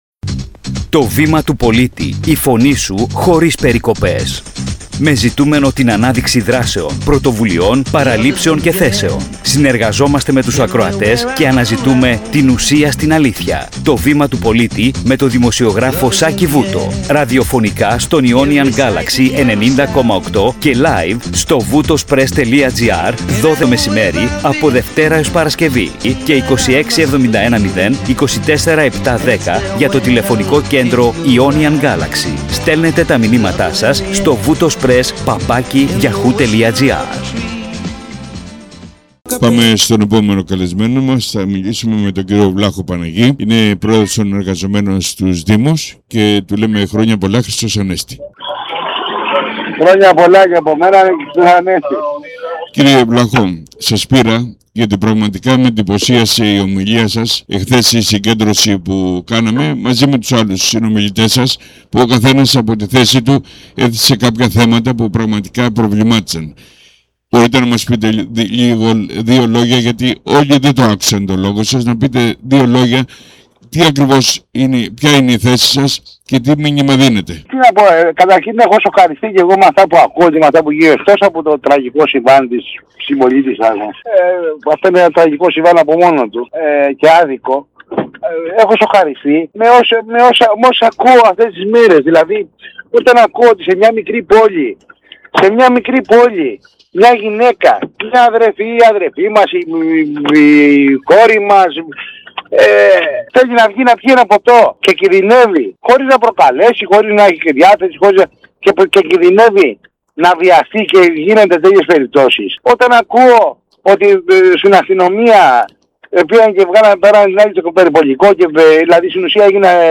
🧾 Περίληψη συζήτησης